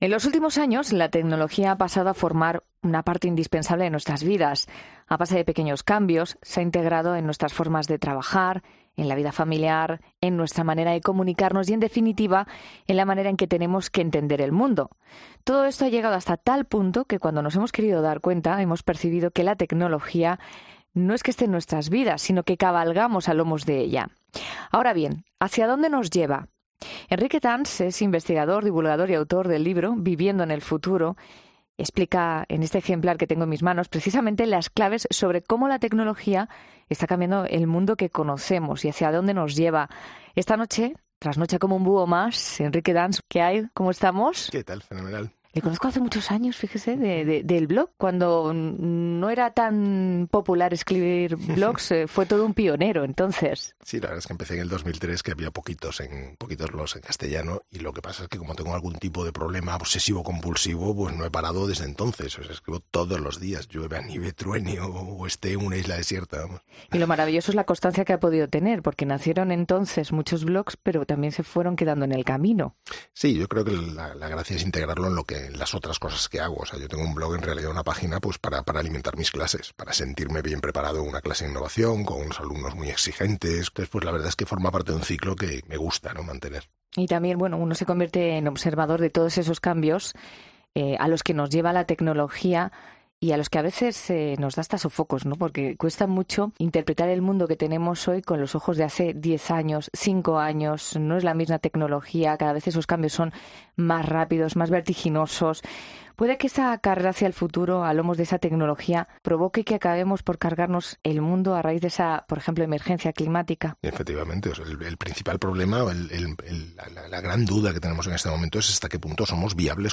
El experto en Tecnología Enrique Dans, en 'La Noche'
ESCUCHA ESTA ENTREVISTA EN 'LA NOCHE' HACIENDO CLIC AQUÍ